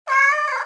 Le chat
il miaule
chat.mp3